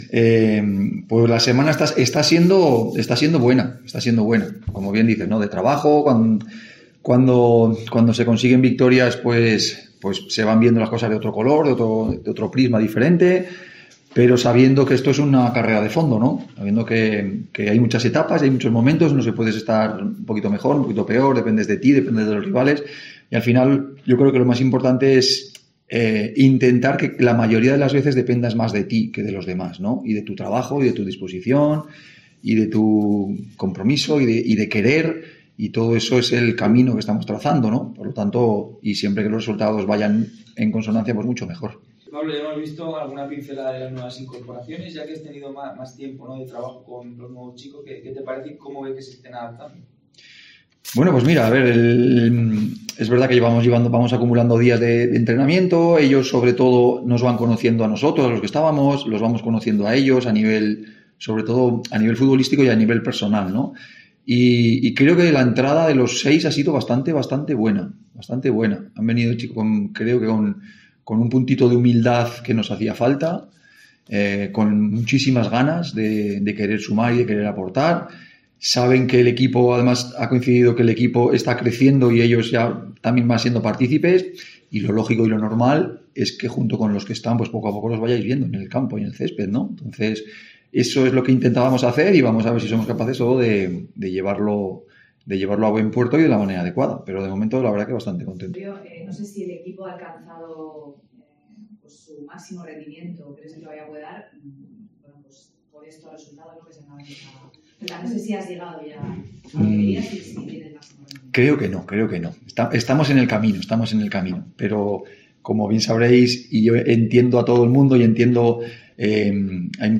Pablo Alfaro atendió a los medios de comunicación en la rueda de prensa previa al partido del sábado, a las ocho de la tarde, en el que el Real Murcia se enfrentará frente el CD Alcoyano en el estadio El Collao .